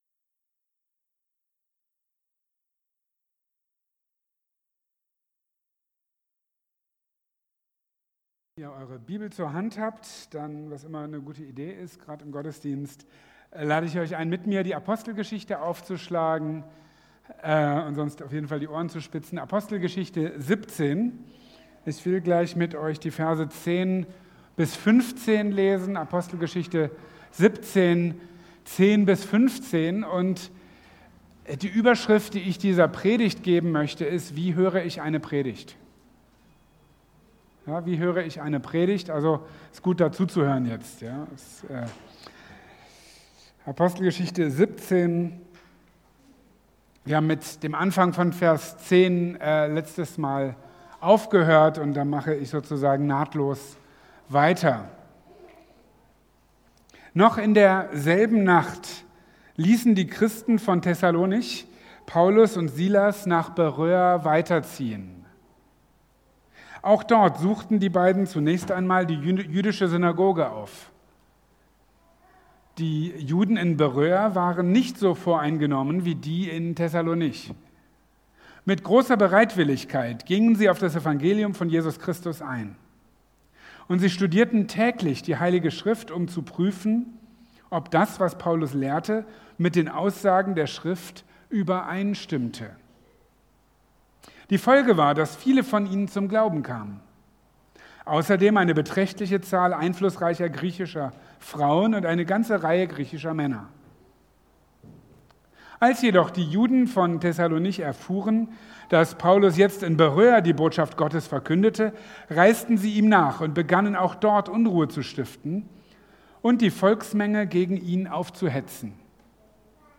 (Apostelgeschichte Teil 43) | Marburger Predigten